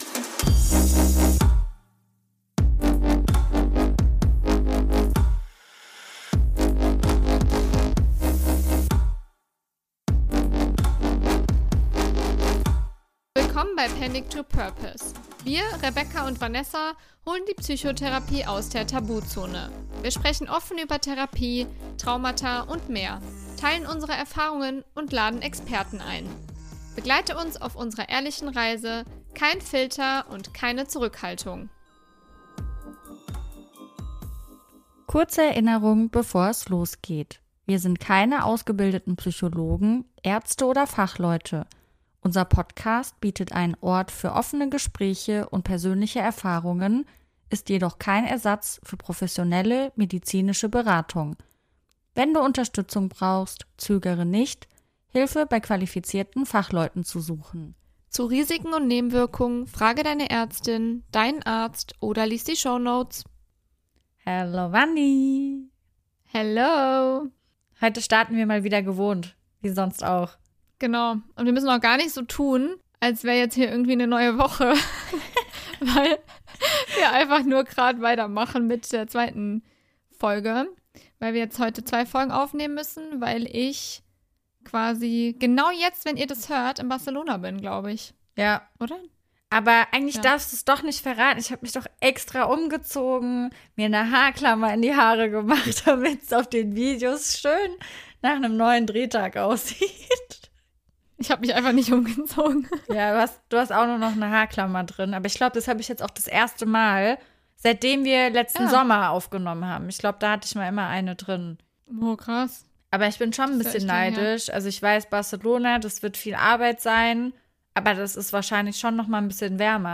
#24 Deine Podcast Hosts Interviewen sich gegenseitig - Hast du das schon über uns gewusst? ~ Panic to Purpose - Dein Mental Health Podcast